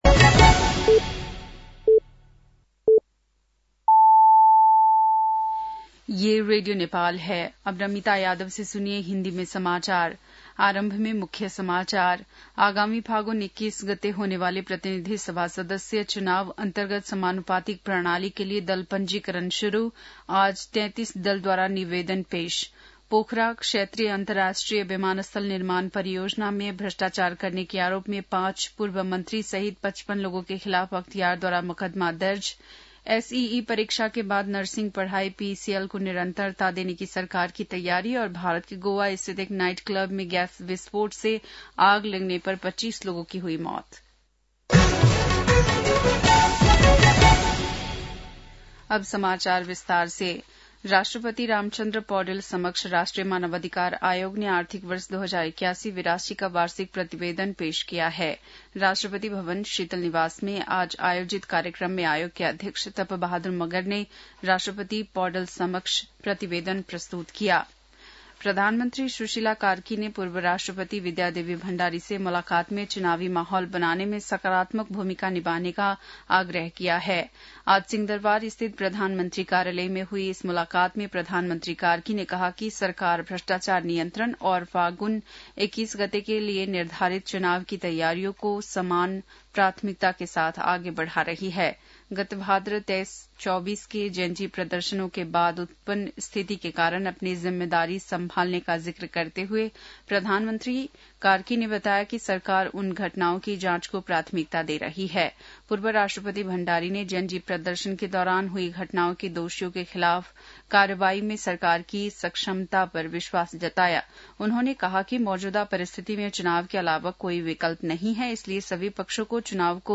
बेलुकी १० बजेको हिन्दी समाचार : २१ मंसिर , २०८२
10-pm-hindi-news-8-21.mp3